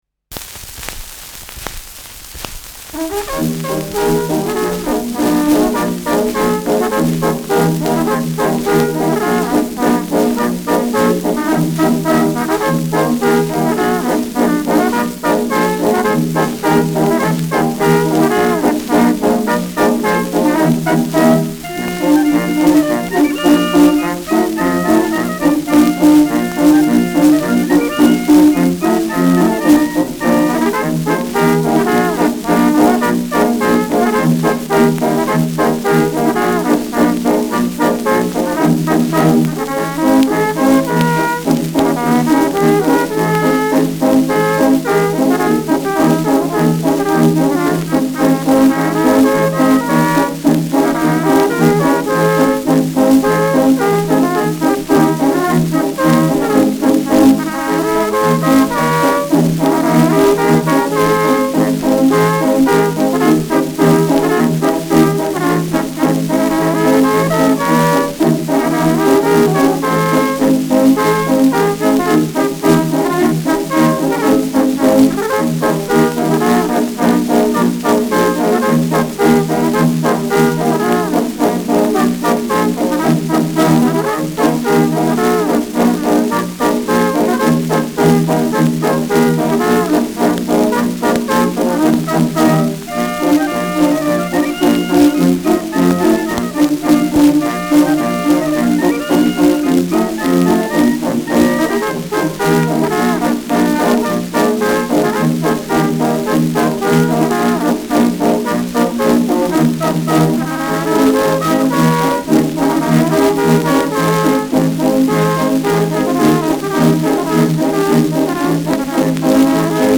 Schellackplatte
[München] (Aufnahmeort)